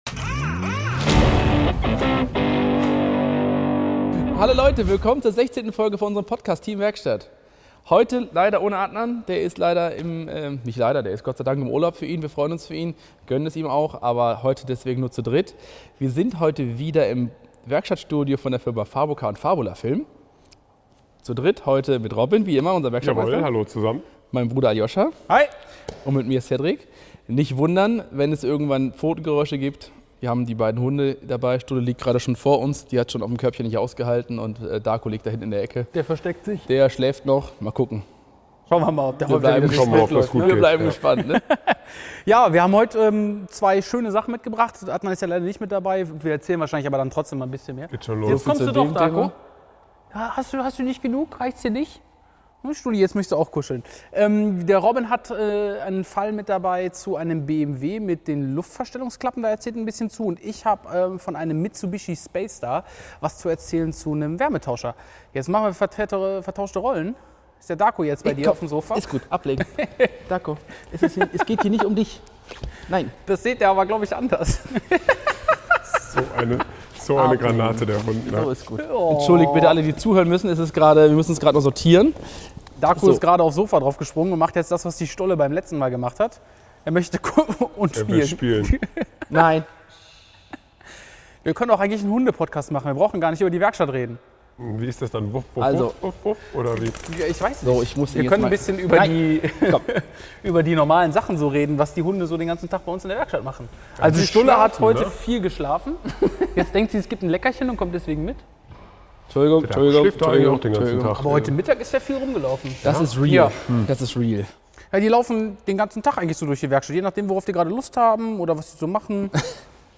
Die Folge 16 wurde im Werkstattstudio von Fabula Film und FabuCar in Schwelm aufgenommen!